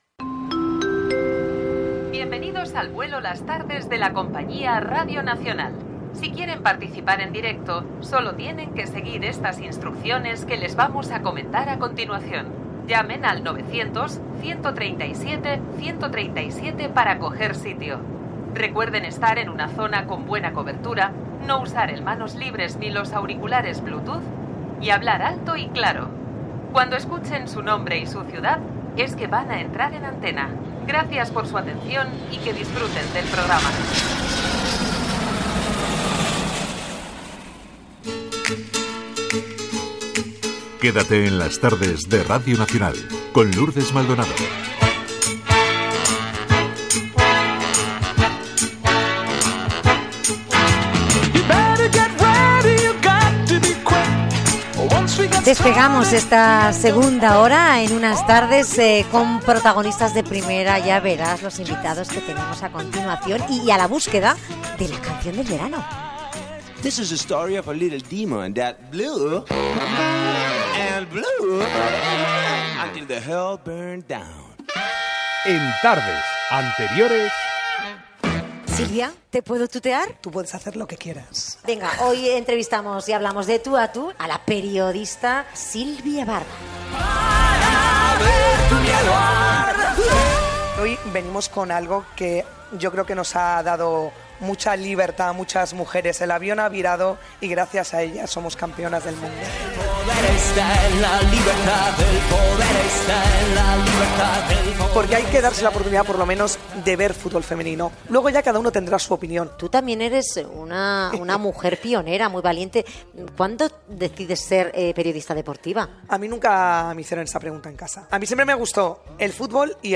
Careta del programa, presentació de la segona hora, resum d'edicions anteriors, indicatiu, els cantants Soledad Giménez i Juan Valderrama parlen de ls seva gira dedicada al bolero. Indiactius del programa i la ràdio.
Entreteniment